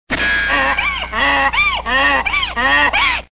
Crying Donkey Sound Effect Free Download
Crying Donkey